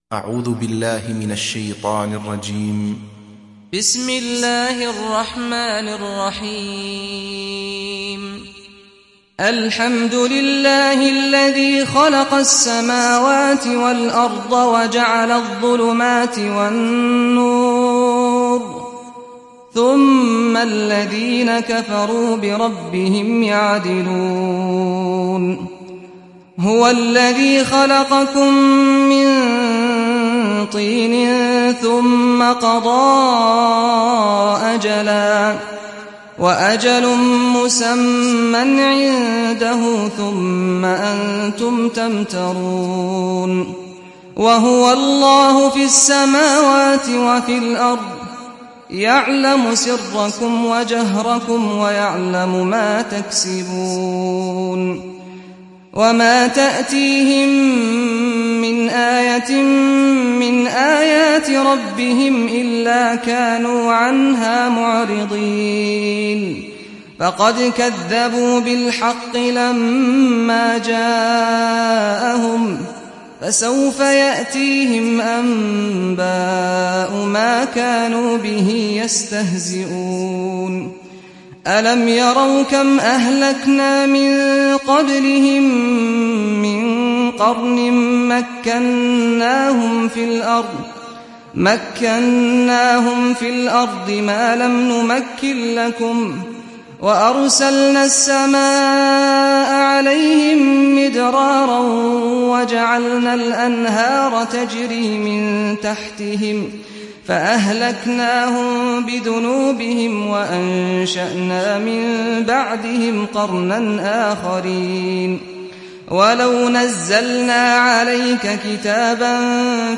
تحميل سورة الأنعام mp3 بصوت سعد الغامدي برواية حفص عن عاصم, تحميل استماع القرآن الكريم على الجوال mp3 كاملا بروابط مباشرة وسريعة